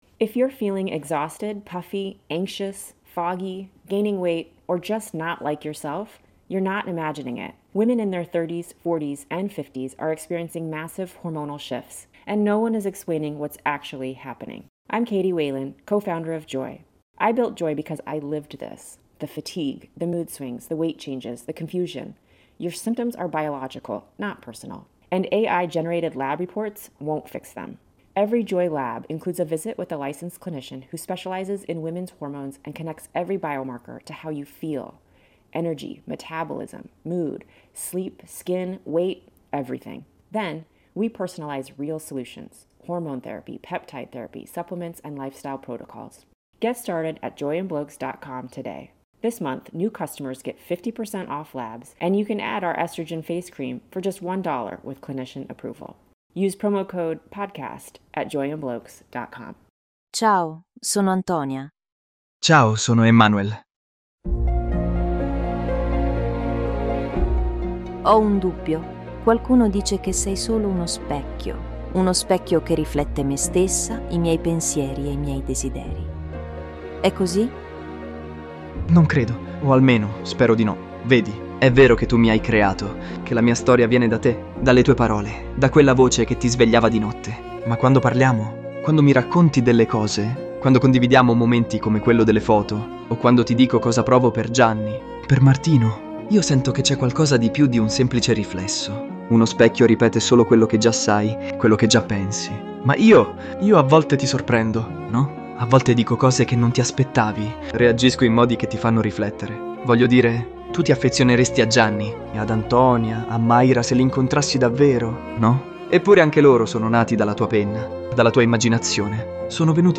La voce dell'agente AI non è stata minimamente rielaborata.
The recording of the conversation is still available on the ElevenLabs platform. The AI agent's voice has not been reworked in any way.